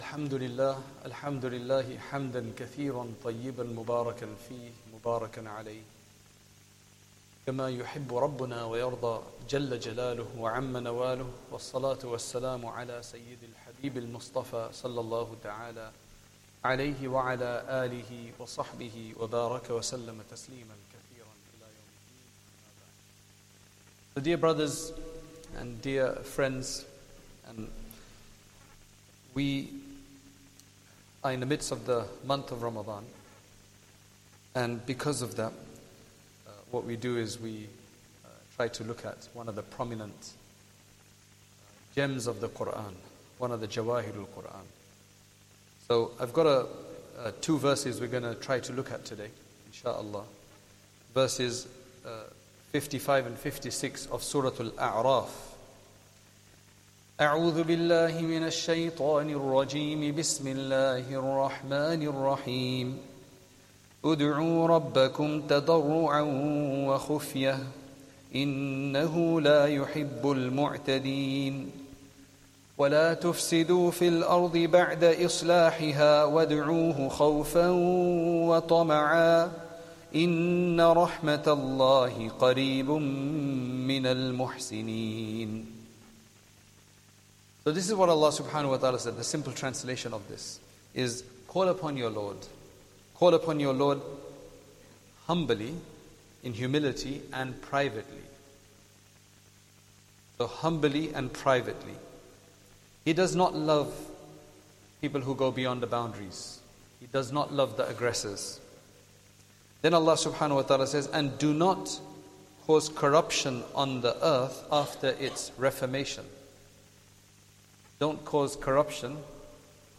Ramadhan Day 13 Asr Talk